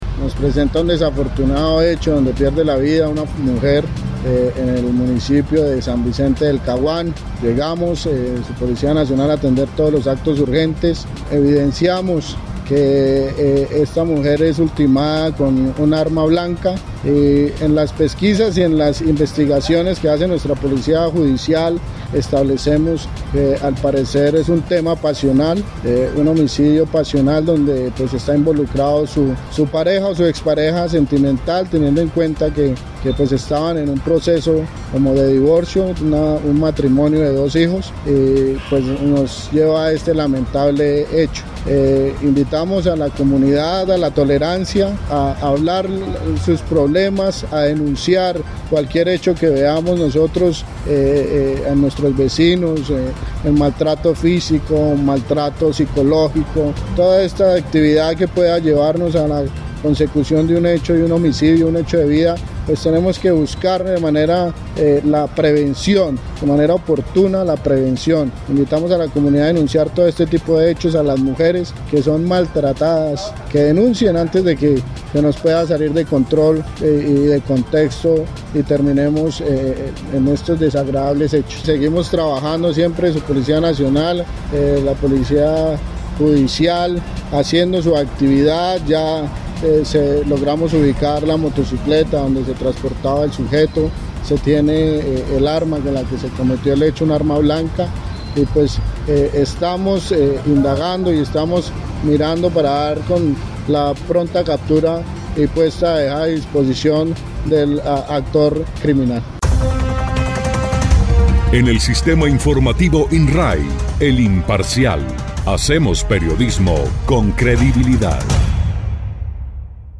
01_CORONEL_ANDRES_PINZON_FEMINICIDIO.mp3